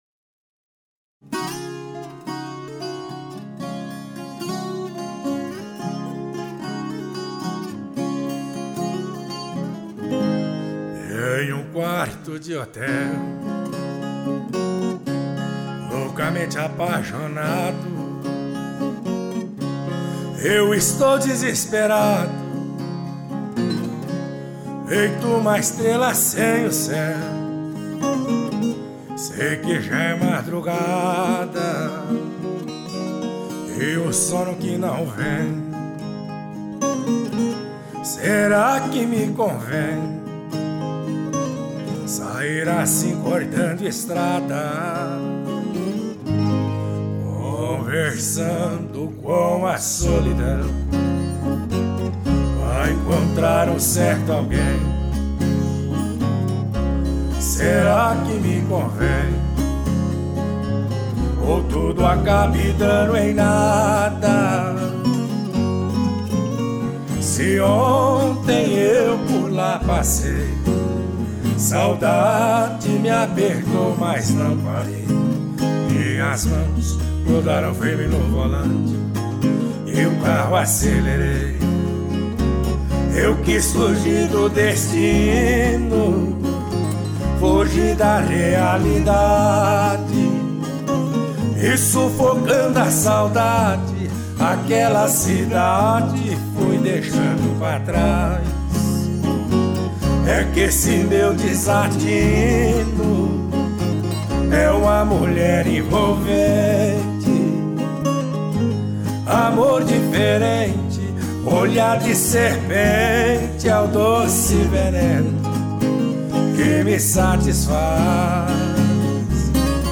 baixo